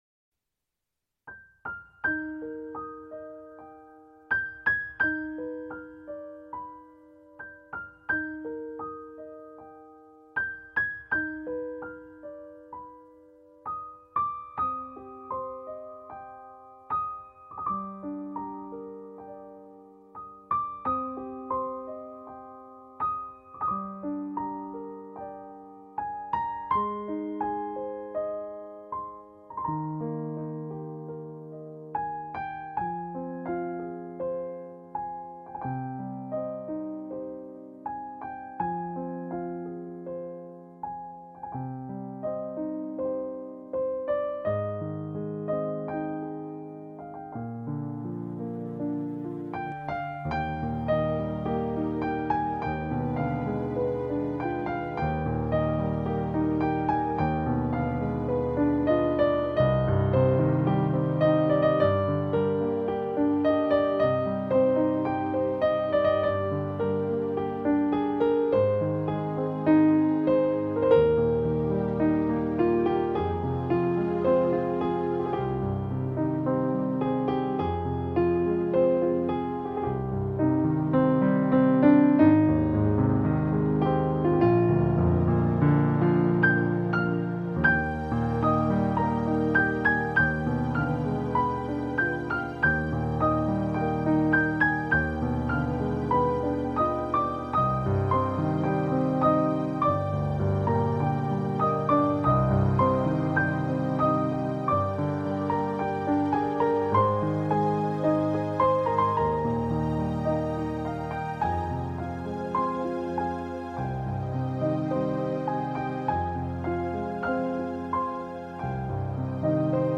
Музыка для мечтаний и вдохновения